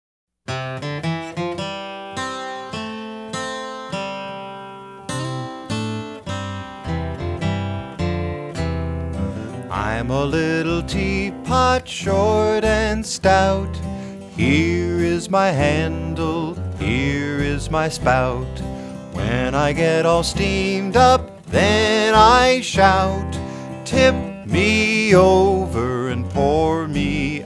This collection of folk song favorites